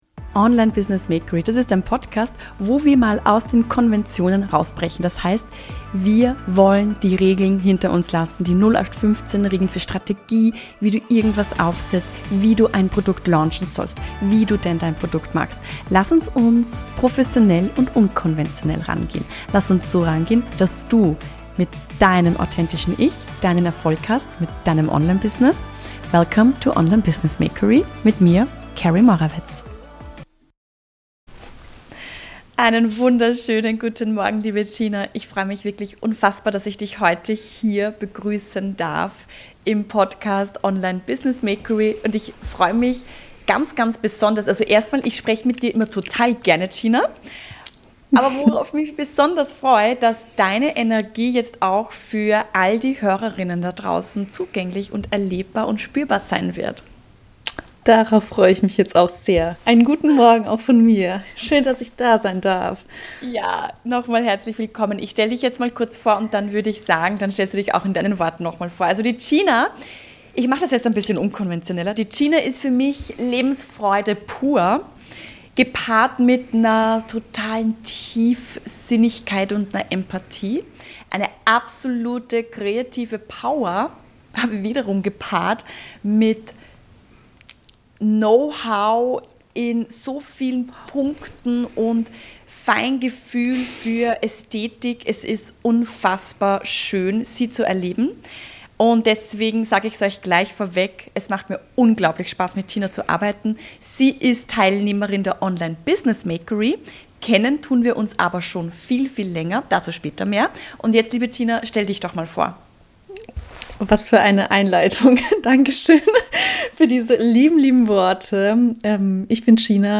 #15 Von offline Workshops zum Online-Kurs (Interview)